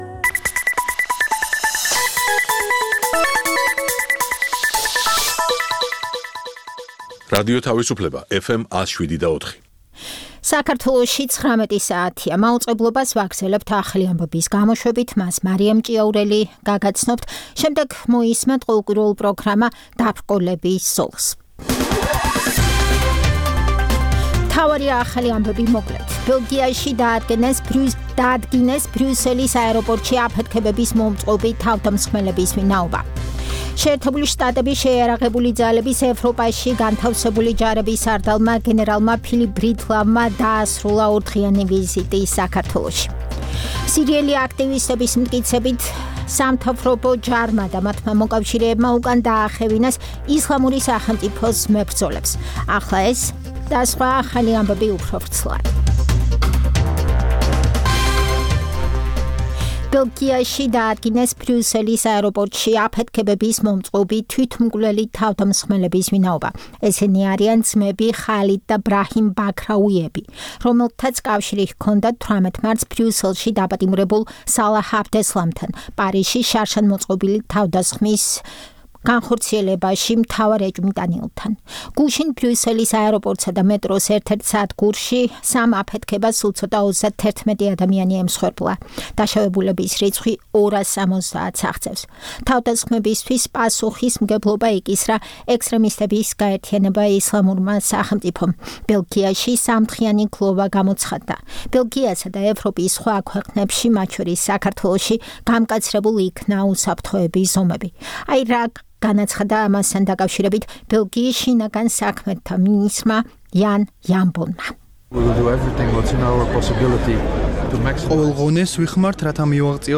საუბარი ირაკლი სესიაშვილთან